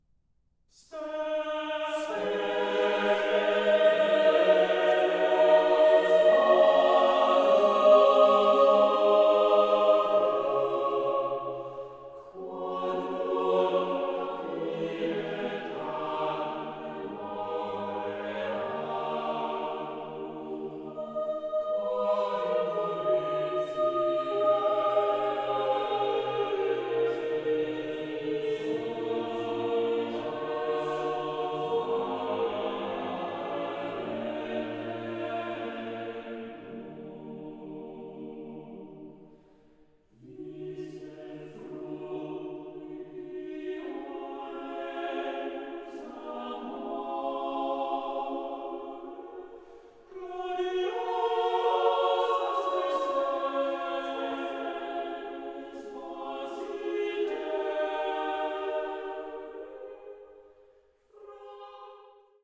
soprano
tenors
organ
sounding revelatory as choral works in sacred guise.